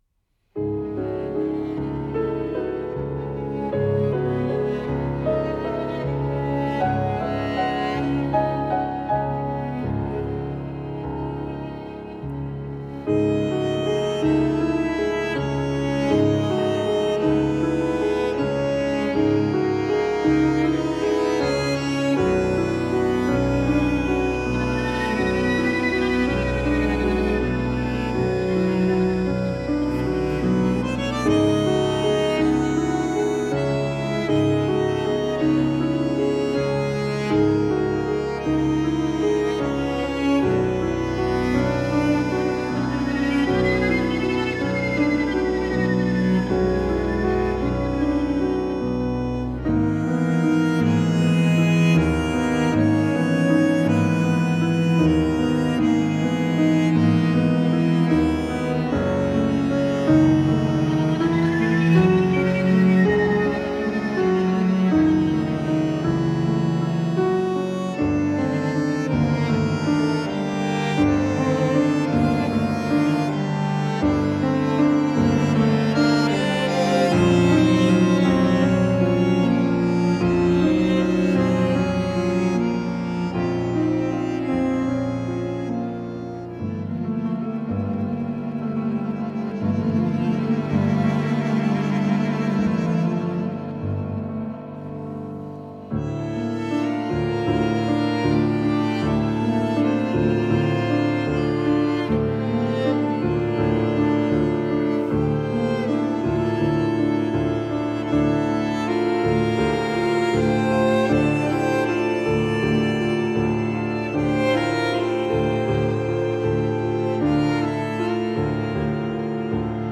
chamber-sized ensemble.
Genre: Tango